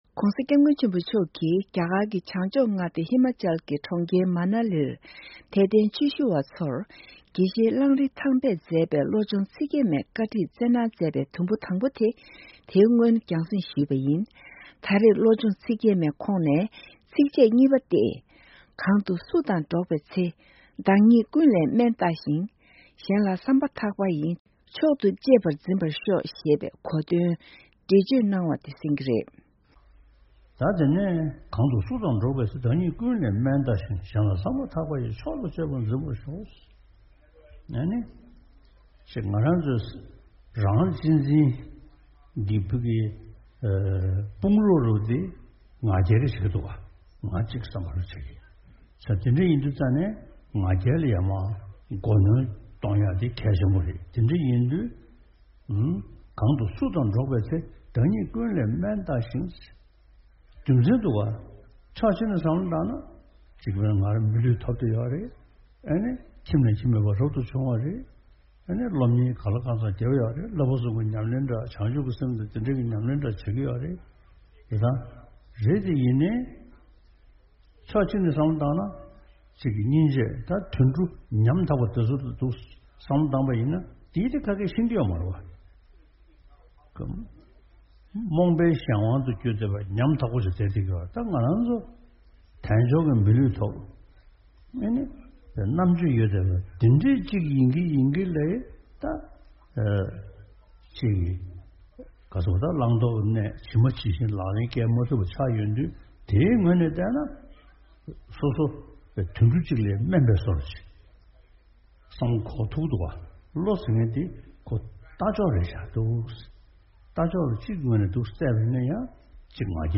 སྤྱི་ནོར་༧གོང་ས་སྐྱབས་མགོན་ཆེན་པོ་མཆོག་གིས་བཀའ་གདམས་པའི་དགེ་བཤེས་གླང་རི་ཐས་པས་མཛད་པའི་བློ་སྦྱོང་ཚིག་བརྒྱད་མའི་བཀའ་ཁྲིད་ཚན་པ་མཐའ་མ་དེ་གསན་རོགས་གནང་།།